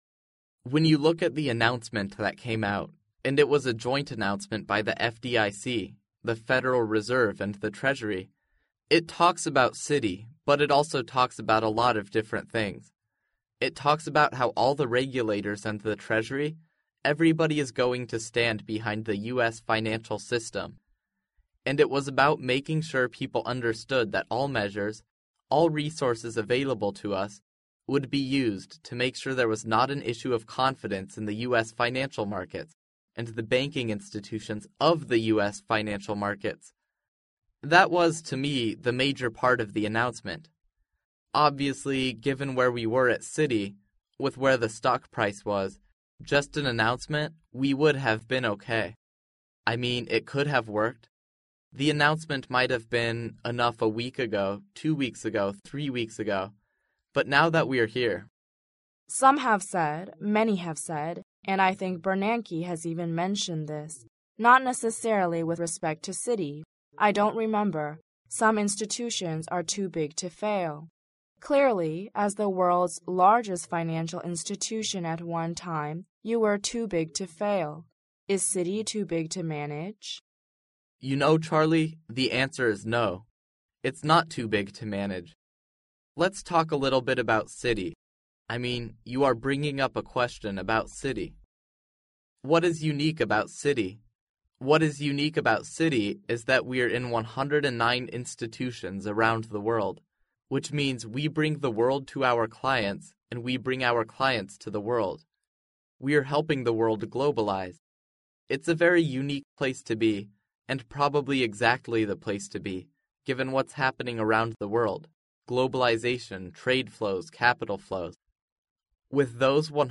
世界500强CEO访谈 第32期:花旗集团潘迪特 面临着信用危机(2) 听力文件下载—在线英语听力室